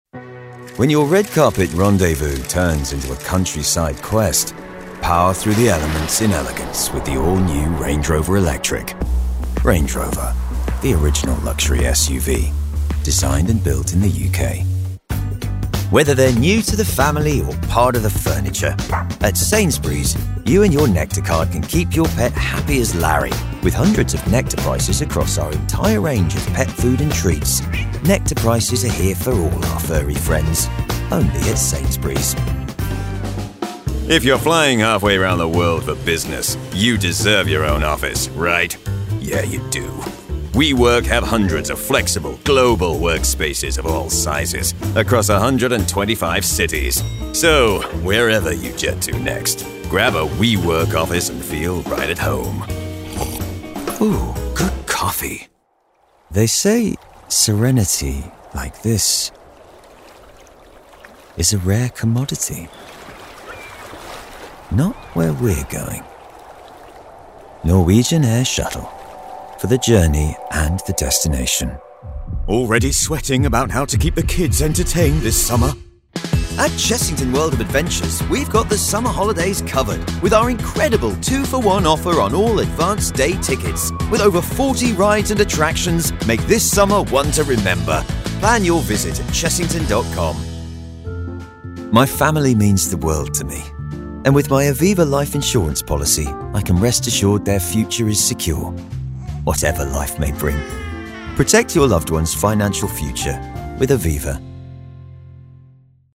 Inglés (Reino Unido)
Versátil
Simpático
Genuino